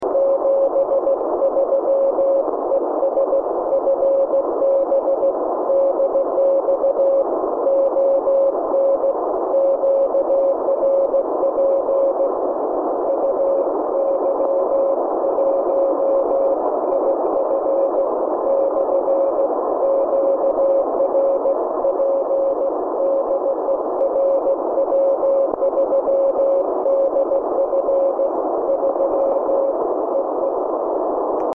В этот раз я использовал только самодельную аппаратуру :) :
одно-ваттный трансивер и передатчик на микросхеме 74HC240.